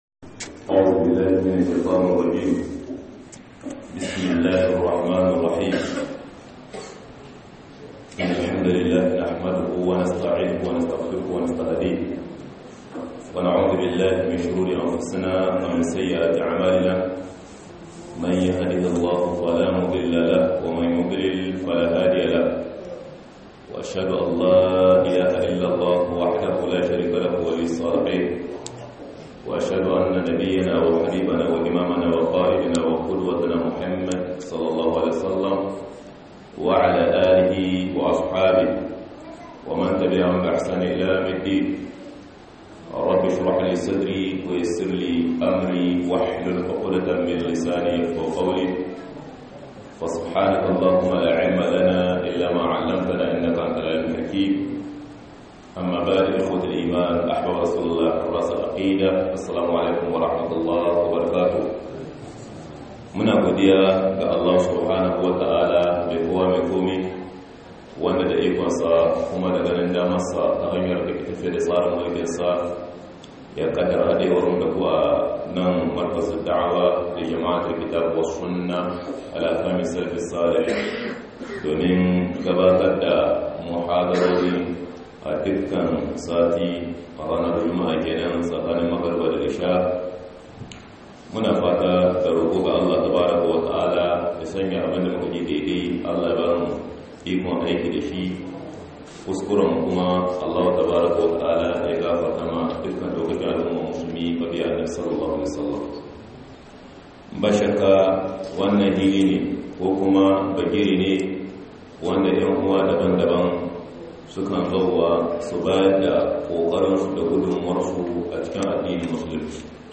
MUHADARA_MARKAZ_DA'AWA_2020_12_11_18'22'42'